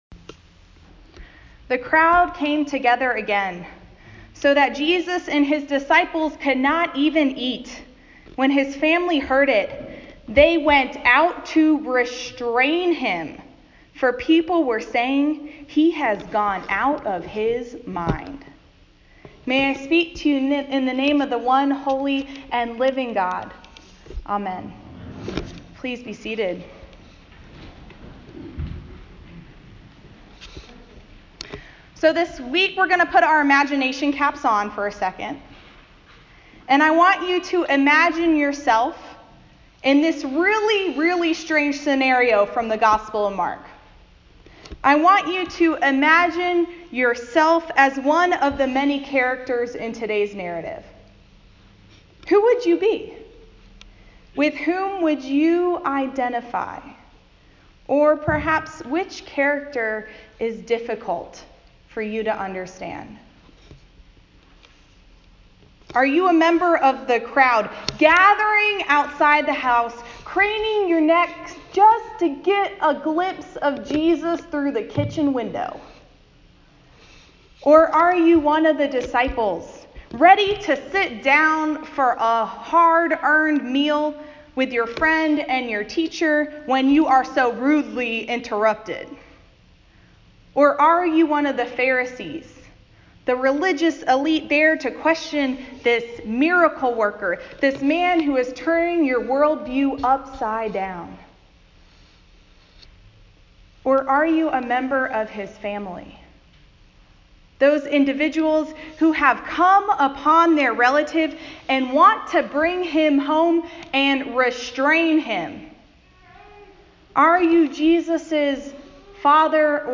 A Sermon for Year B, Proper 5 Mark 3:20-35